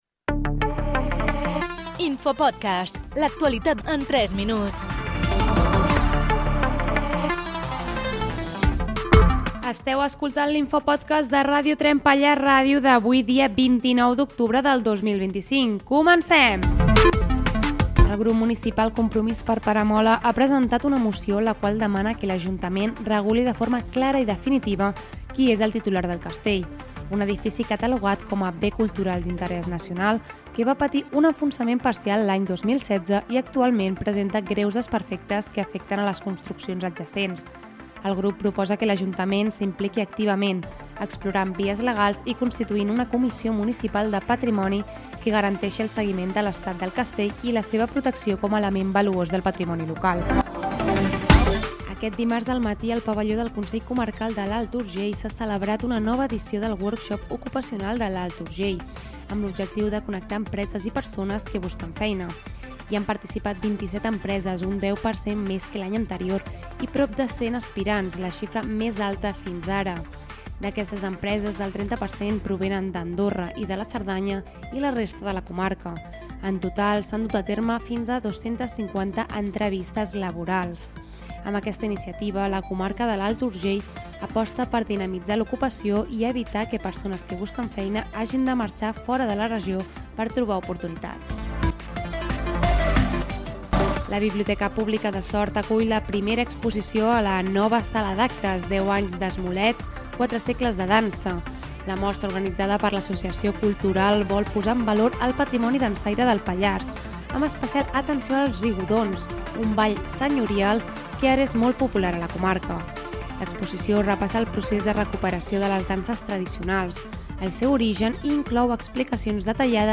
Careta del programa i resum informatiu